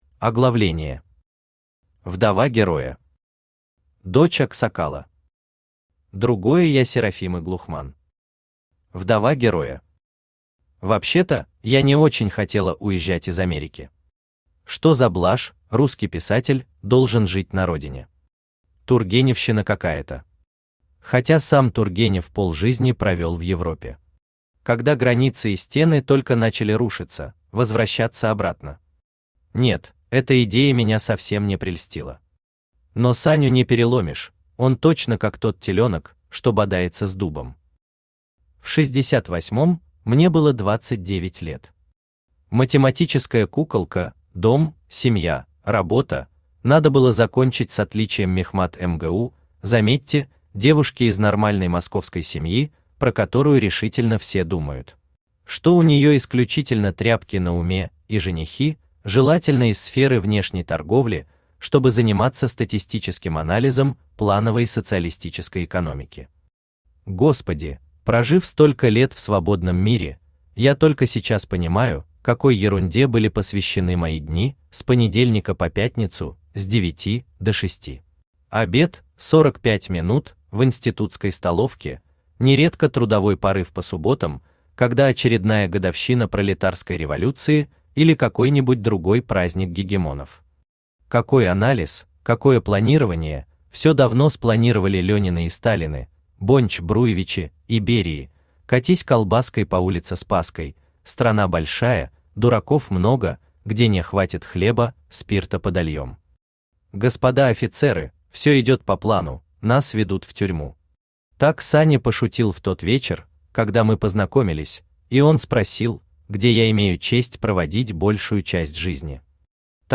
Aудиокнига Вдова героя Автор Роман Воликов Читает аудиокнигу Авточтец ЛитРес.